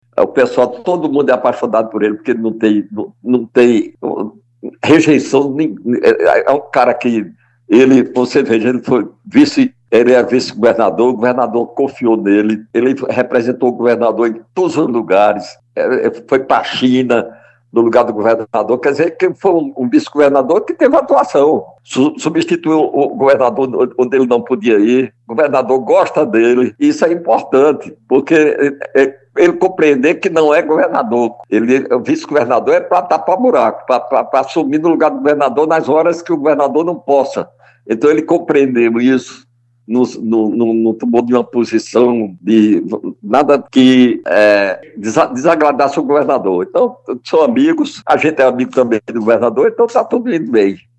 Os comentários do dirigente foram registrados pelo programa Correio Debate, da 98 FM, de João Pessoa, nesta quinta-feira (09/01).